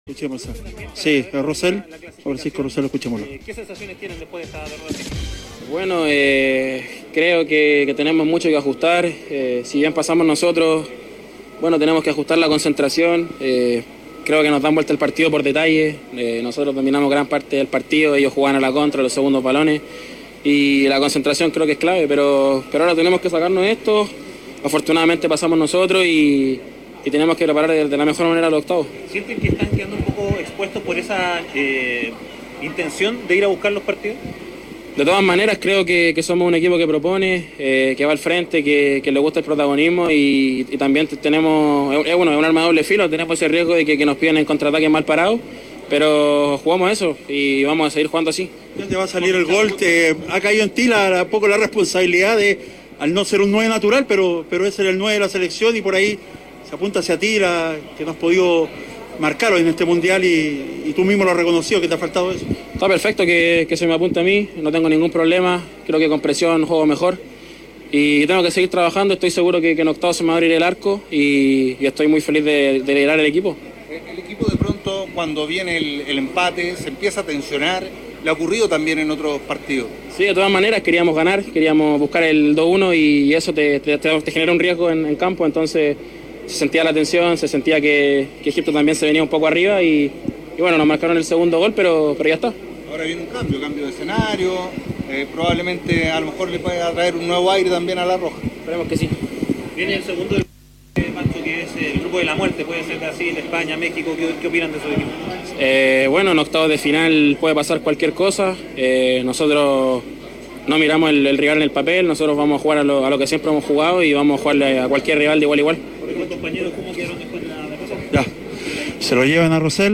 en zona mixta